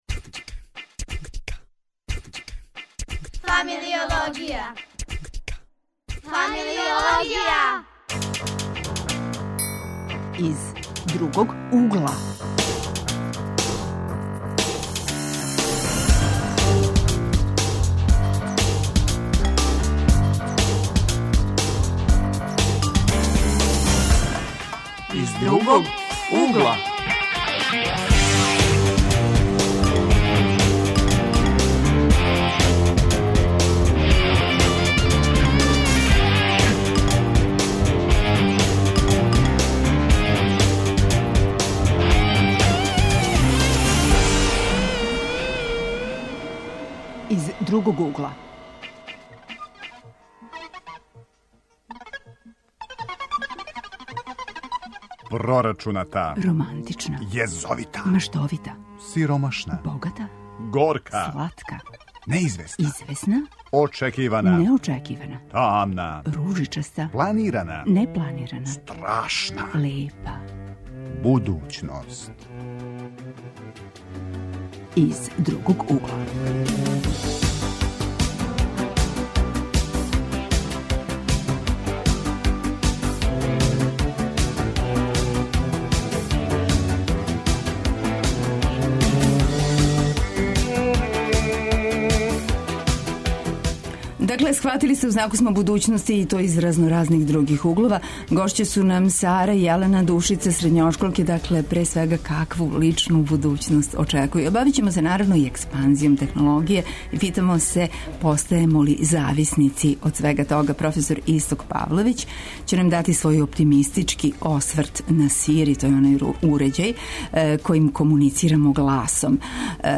Гости су нам средњошколци.